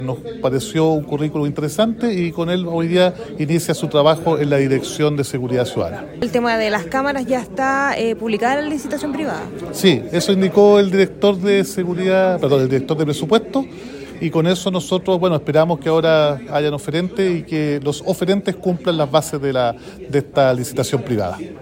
Una designación que destacó el alcalde de Temuco, Roberto Neira, quien también se refirió a la licitación privada para encontrar a la nueva empresa que se hará cargo de operar las 69 cámaras de seguridad que no están funcionando en el centro de la ciudad.
cuna-director-2-alcalde.mp3